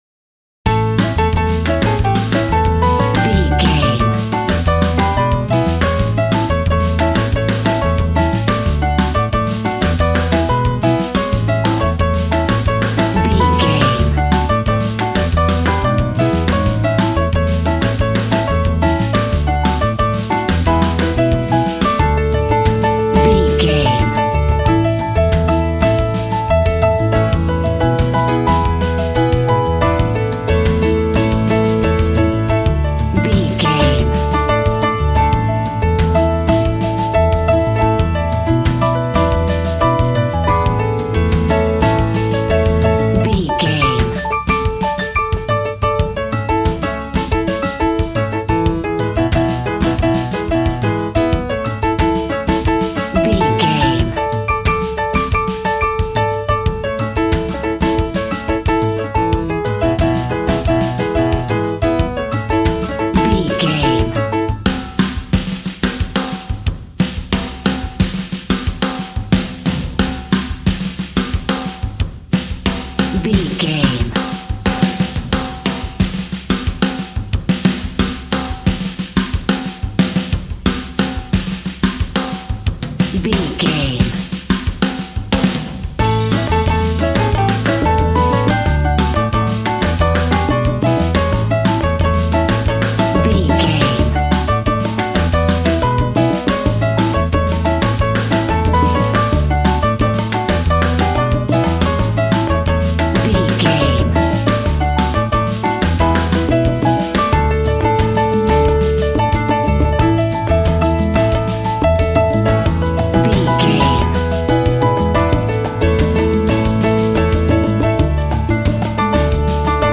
An exotic and colorful piece of Espanic and Latin music.
Ionian/Major
funky
energetic
romantic
percussion
electric guitar
acoustic guitar